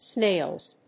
/snelz(米国英語), sneɪlz(英国英語)/